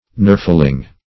nerfling - definition of nerfling - synonyms, pronunciation, spelling from Free Dictionary Search Result for " nerfling" : The Collaborative International Dictionary of English v.0.48: Nerfling \Nerf"ling\, n. (Zool.)